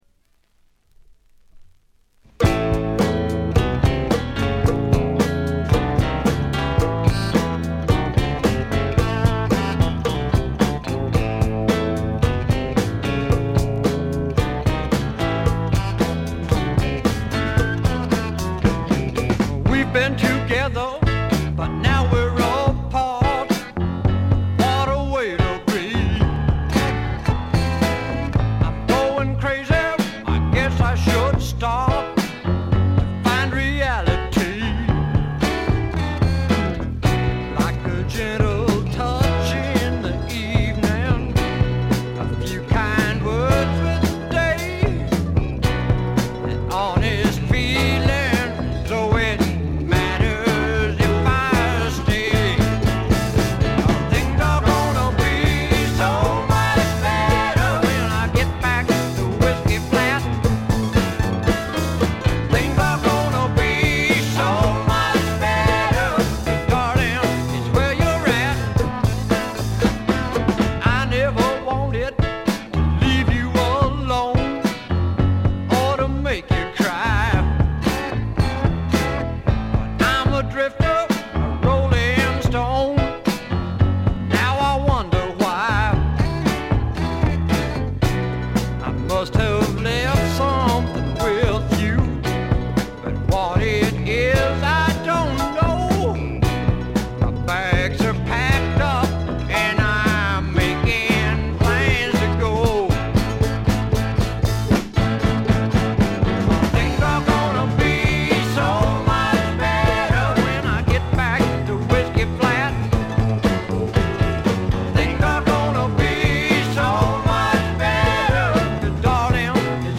ほとんどノイズ感無し。
試聴曲は現品からの取り込み音源です。
Guitar and Vocals